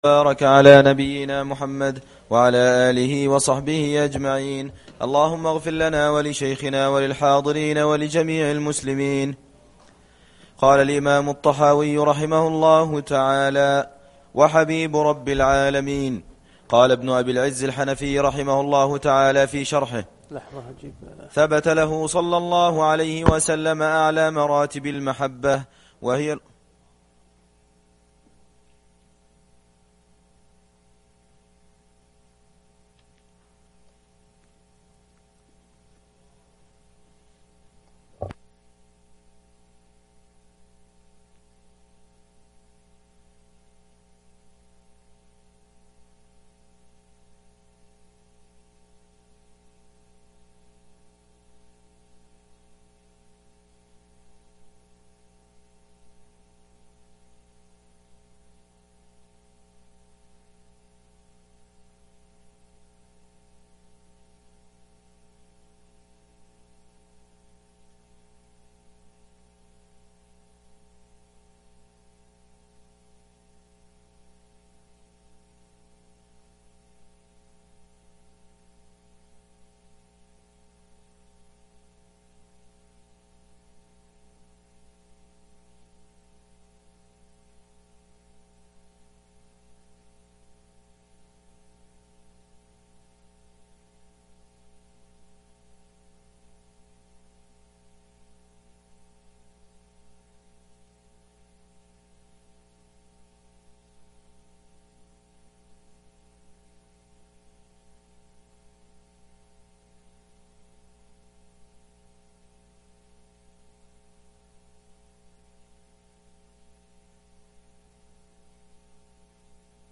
الدرس 16 (أعلى مراتب المحبة) العقيدة الطحاوية